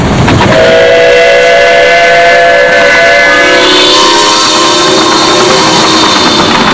Featuring the double headed Niagara and Berkshire special at Stapleford Steam 99. Pulling an 18 coach train, the locos are heard climbing the bank into the tunnel, and the whistle (chimes) evoke a surreal atmosphere, especially at night as they echo over the fields.
The first chime heard is the NKP Berkshire followed by the leading engine, the NYC Niagara.